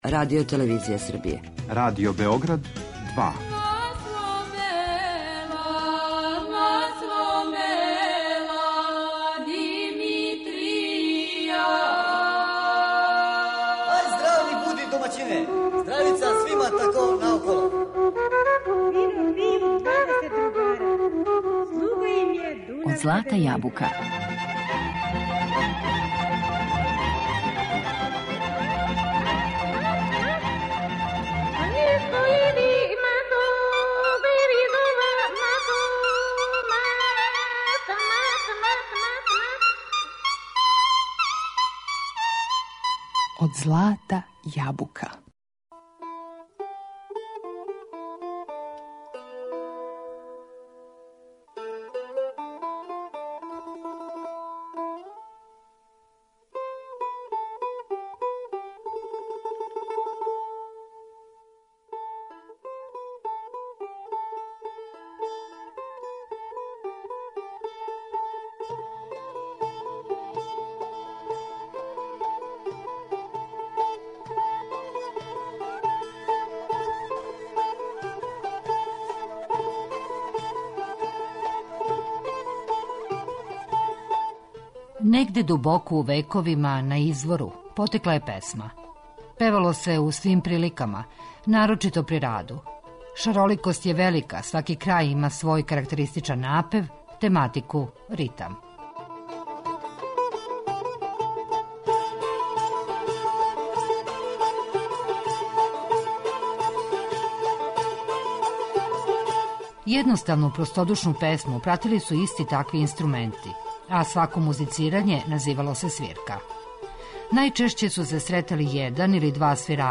У смислу трајања и наслеђа, у данашњој емисији слушаћете традиционалне народне песме обучене у рухо нашег времена.
Представићмо певаче, групе и ансамбле, који, свако на свој начин, доноси традиционалну песму и старе записе.